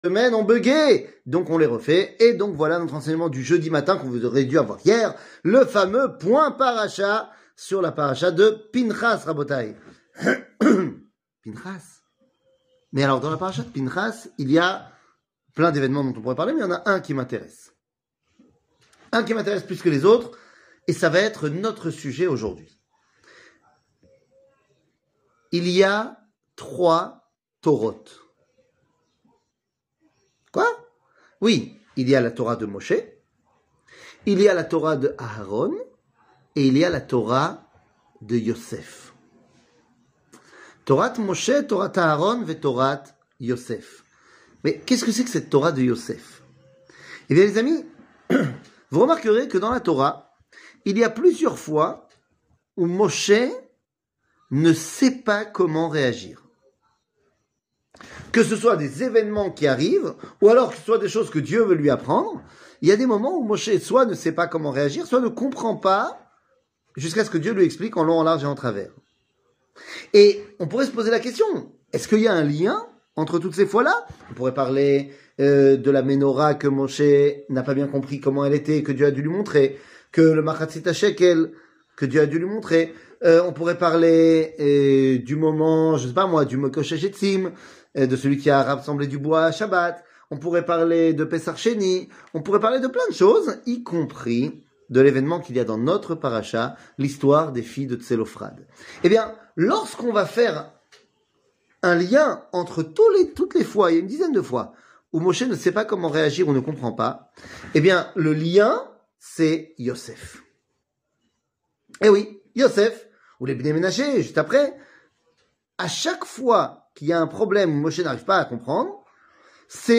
Le point Paracha, Pinhas, La Torah de Yossef 00:05:49 Le point Paracha, Pinhas, La Torah de Yossef שיעור מ 07 יולי 2023 05MIN הורדה בקובץ אודיו MP3 (5.31 Mo) הורדה בקובץ וידאו MP4 (9.34 Mo) TAGS : שיעורים קצרים